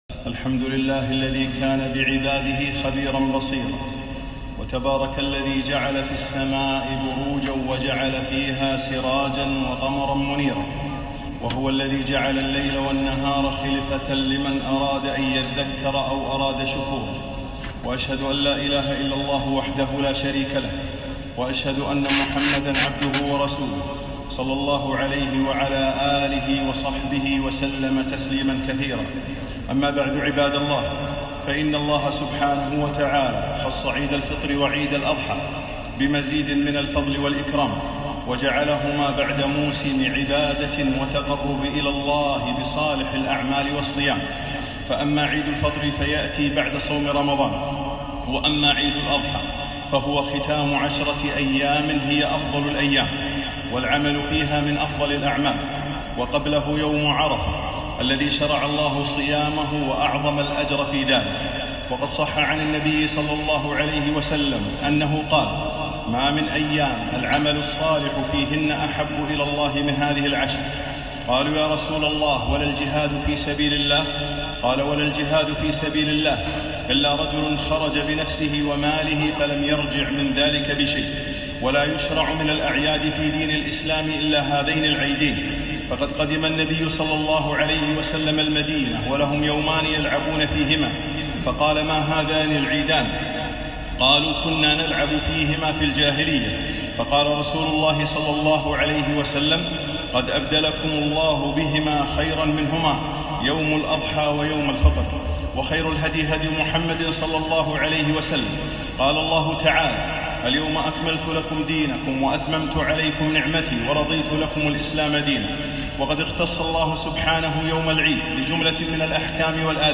خطبة عيد الأضحى ( أحكام العيدين) 10ذي الحجة 1442 هـ